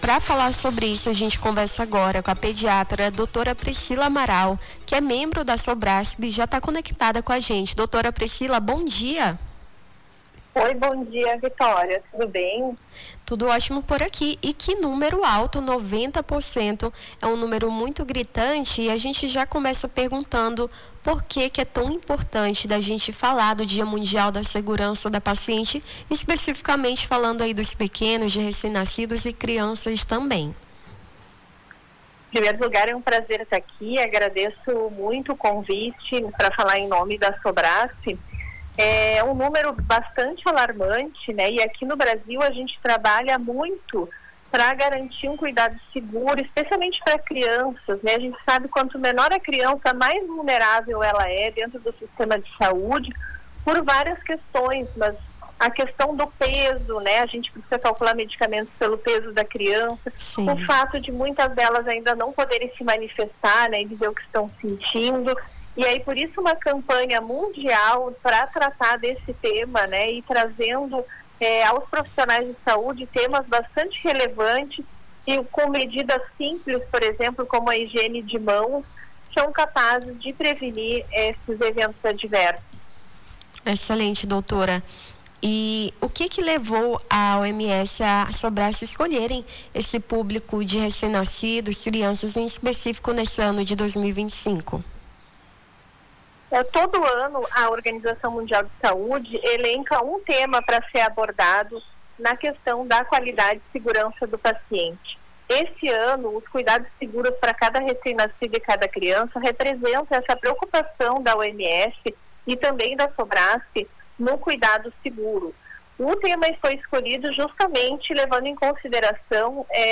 Nome do Artista - CENSURA - ENTREVISTA (DIA MUNDIAL DA SEGURNAÇA DO PACIENTE) 16-09-25.mp3